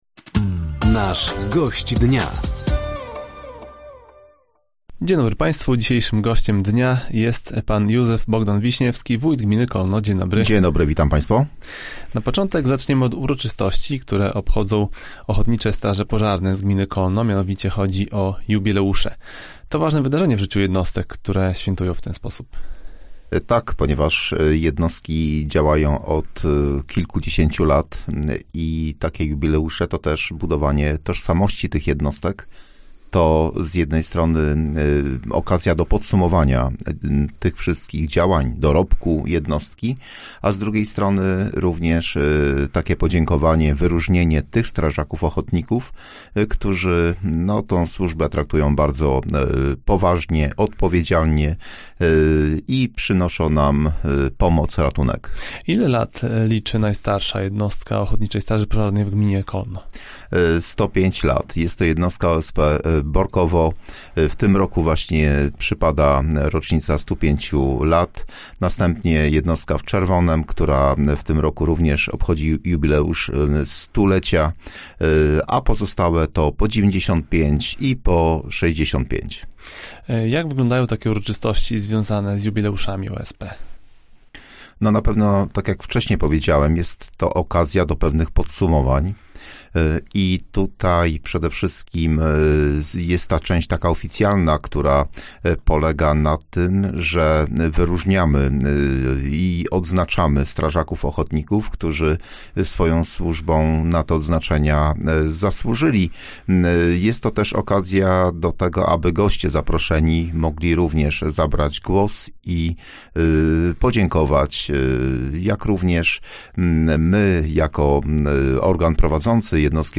Gościem Dnia Radia Nadzieja był wójt Gminy Kolno, Józef Bogdan Wiśniewski. Tematem rozmowy były między innymi jubileusze Ochotniczych Straży Pożarnych, fundusze sołeckie, festyny Kół Gospodyń Wiejskich oraz uroczystości w Janowie.